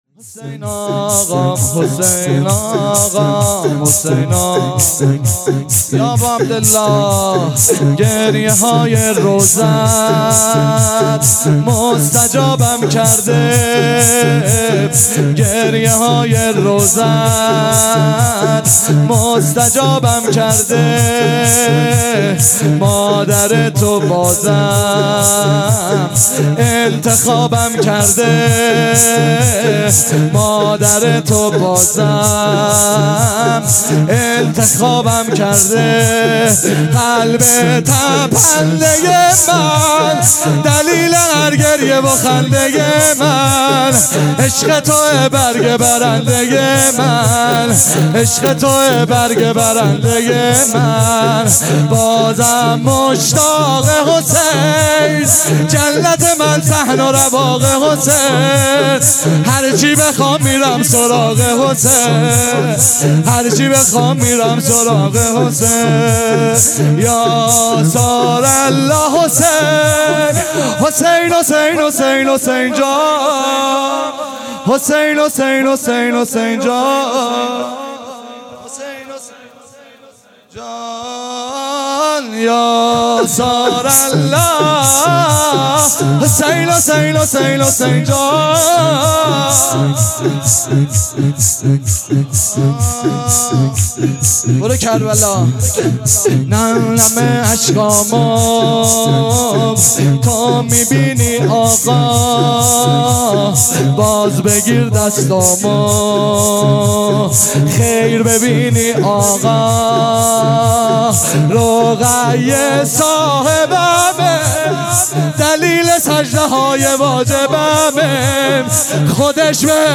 شور - گریه های روضت مستجابم کرده
روضه هفتگی